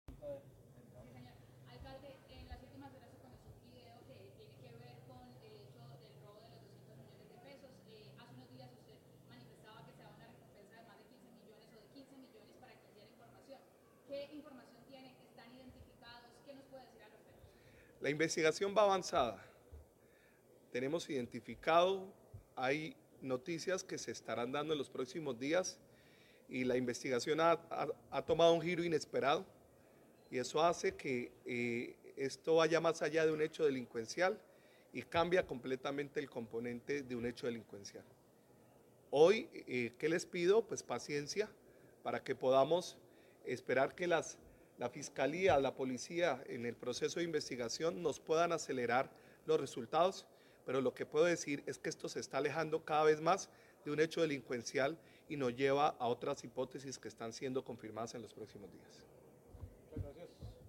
Declaraciones Jaime Andrés Beltrán, Alcalde de Bucaramanga
En rueda de prensa, el alcalde de Bucaramanga, Jaime Andrés Beltrán, sorprendió con sus declaraciones al revelar un giro inesperado en la investigación del robo de más de $200 millones, que se ejecutó en el barrio la Concordia de Bucaramanga.